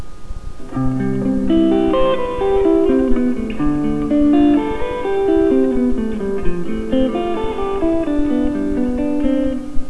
Arpeggio exercise
The illustration below shows an arpeggio exercise using the chords of C, Dm, F and G. This exercise should be mastered.
This exercise uses the notes and chords from the key of C major.
arpeggio exercise.wav